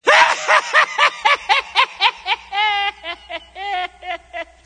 Ouça e baixe a risada maligna de Chucky, o Brinquedo Assassino.
risada-chucky-brinquedo-assassino.mp3